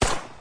Enemy_attack.mp3